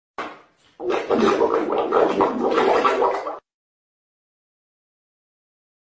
FOX STUDIOS FARTS IS DISGUSTING